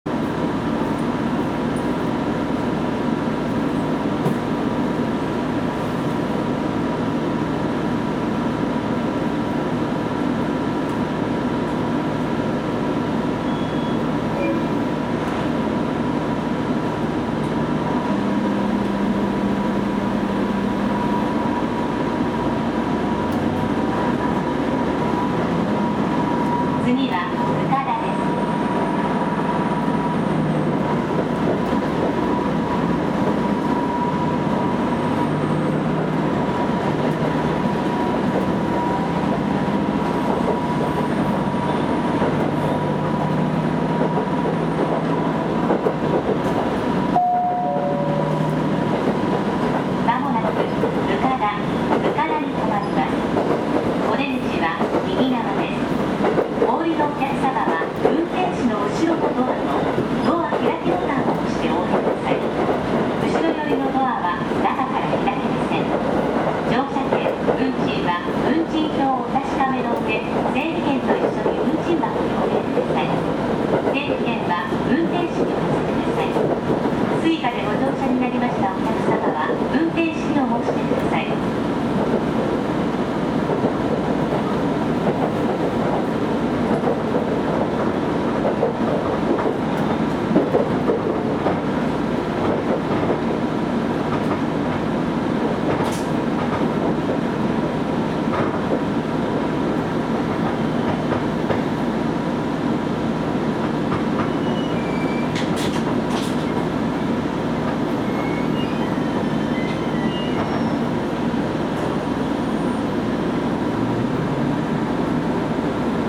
走行音
録音区間：南酒出～額田(お持ち帰り)